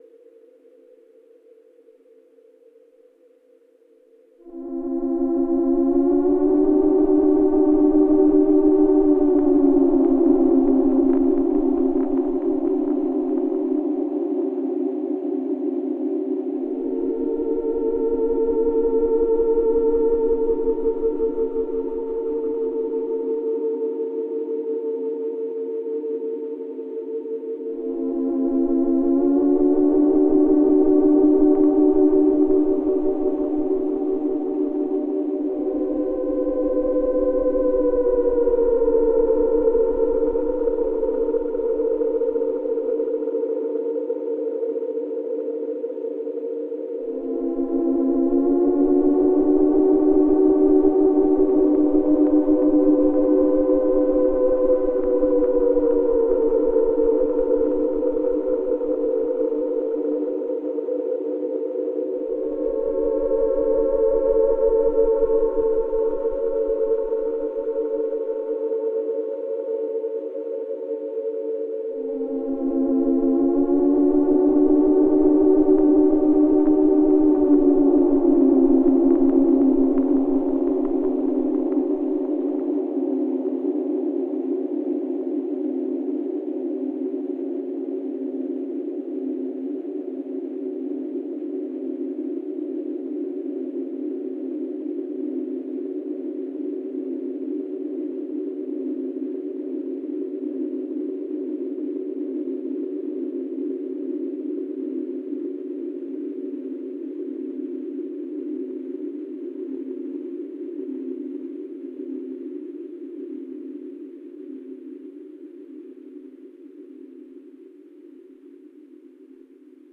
Here are a few examples with just my prophet 5 and Meraki.
The texture and slight breakup you get from this thing is incredible. You can also just ride the edge of oscillation so easily.
(Un-) Fortunately, I don’t like the clipping that the feedback causes.